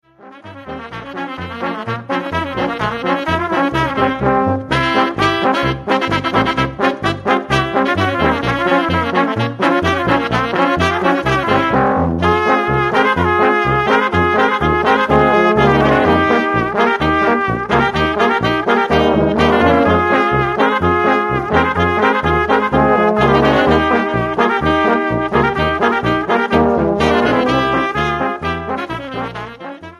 59 Minuten und 33 Sekunden Tanzmusik & Hörvergnügen